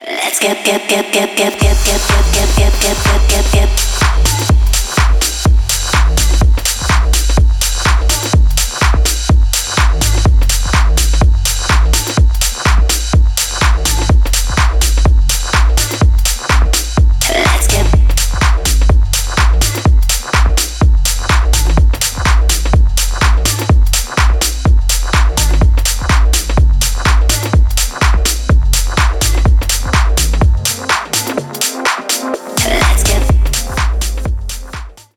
• Качество: 320, Stereo
Tech House
G-House
клубная музыка
Техно